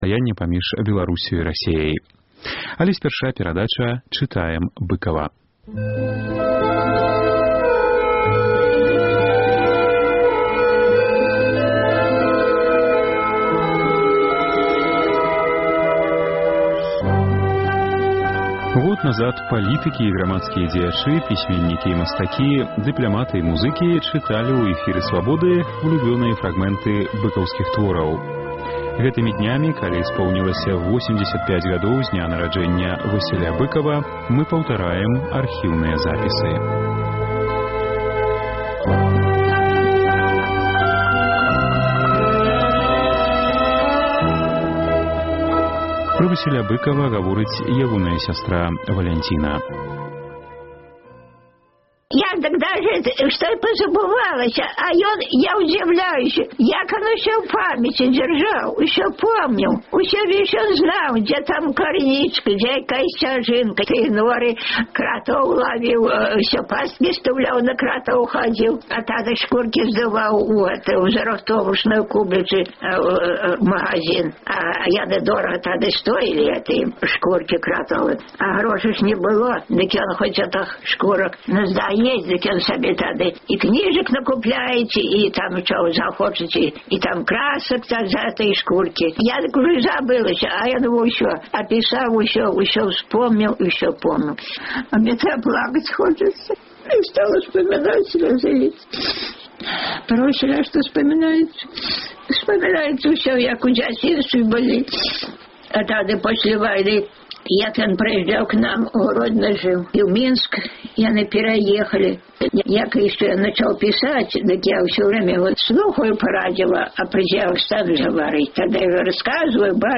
Год таму палітыкі і грамадзкія дзеячы, пісьменьнікі і мастакі, дыпляматы і музыкі чыталі ў эфіры Свабоды ўлюблёныя фрагмэнты быкаўскіх твораў. Гэтымі днямі, калі споўнілася 85-гадоў з дня нараджэньня Васіля Быкава, мы паўтараем архіўныя запісы.